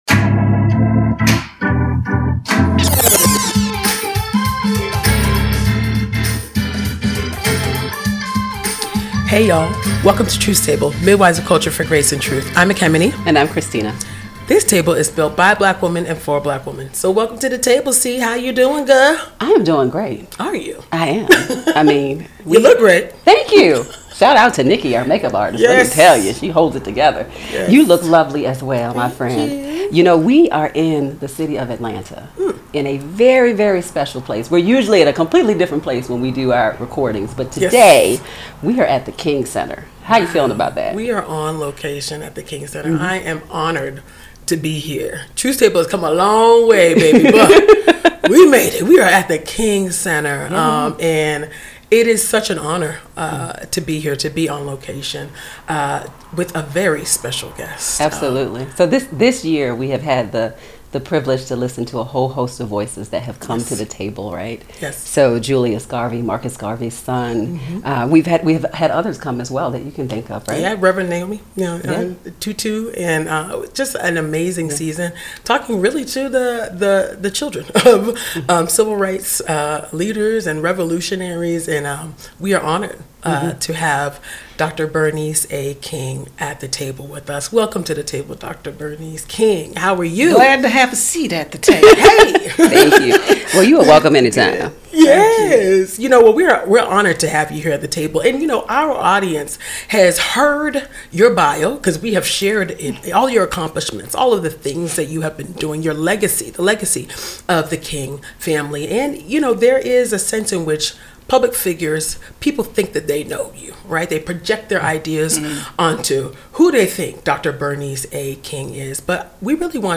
We talk about Dr. King’s legacy of nonviolent resistance and its place in our current political climate. Dr. King speaks candidly, describing the times we are living in as “diabolical.” We discuss Ai, ICE, Palestine, Congo, and more.